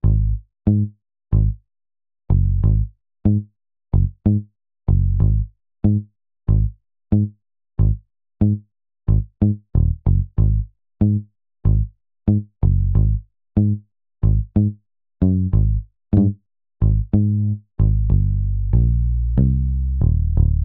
08 bass C.wav